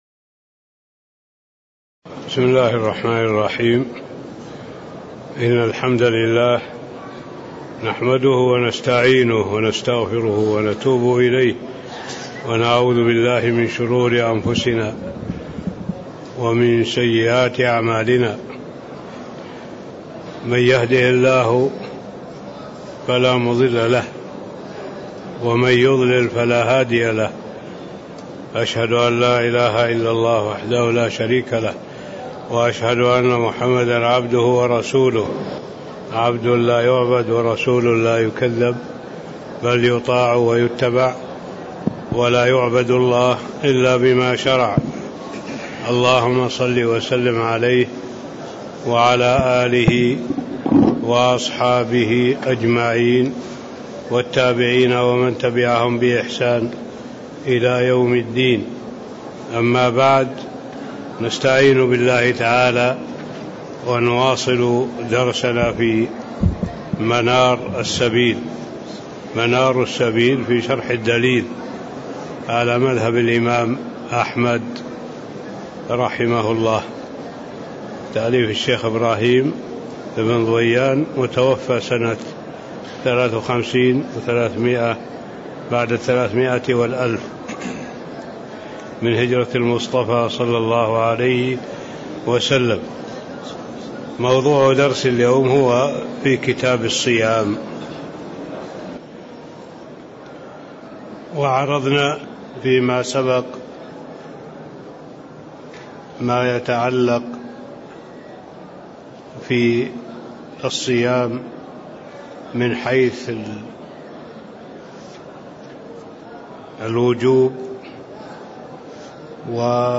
تاريخ النشر ١٩ شعبان ١٤٣٦ هـ المكان: المسجد النبوي الشيخ